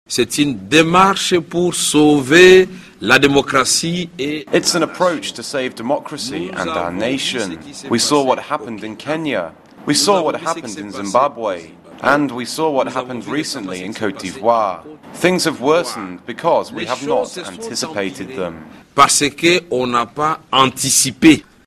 drc_son_karmerhe_dubbed.mp3